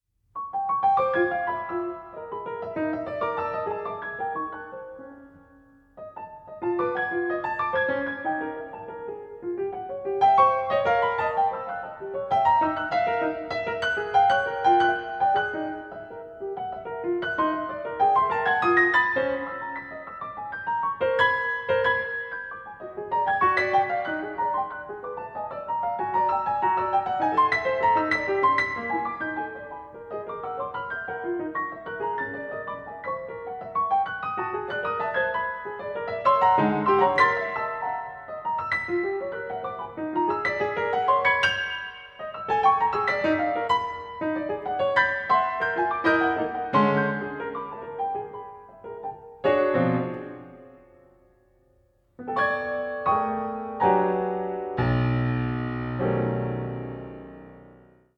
piano
Birds and Landscapes